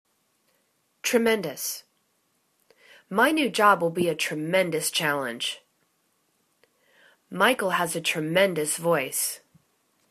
tre.men.dous     /tri'mendəs/    adj